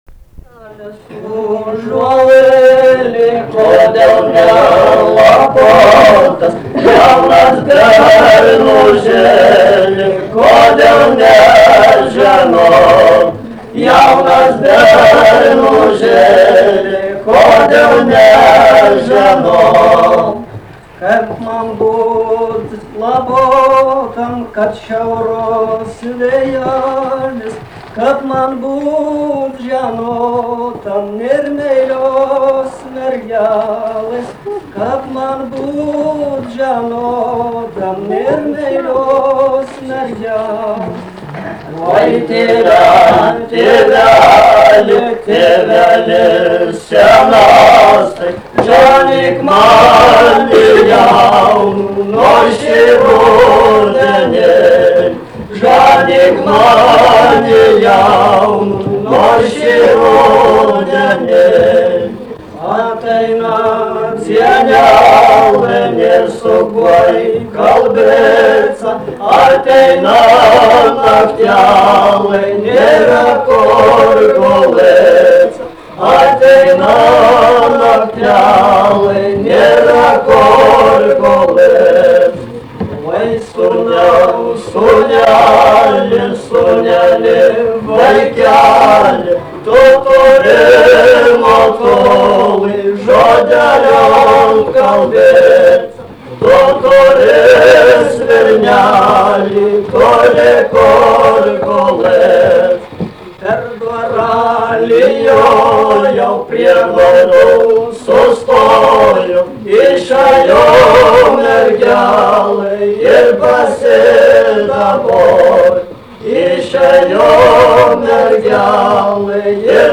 Vyrų grupė
daina
Mardasavas
vokalinis
2 balsai